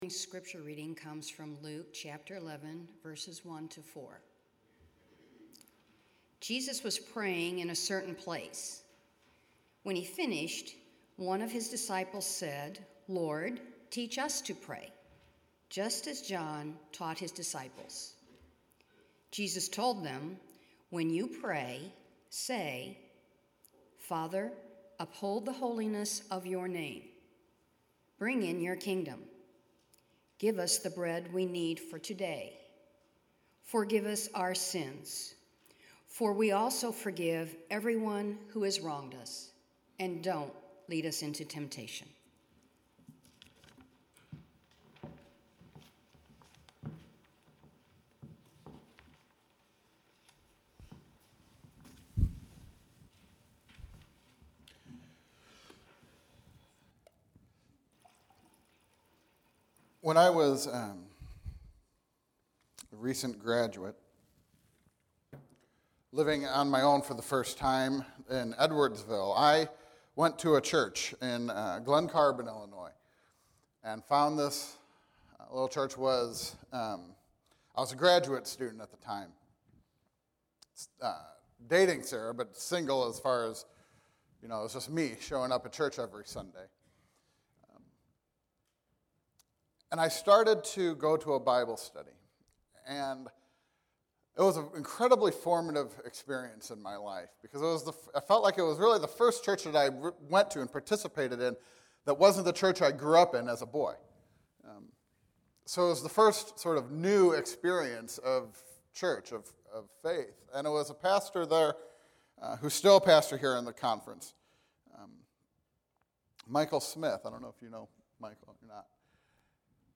Sermon: The prayer we live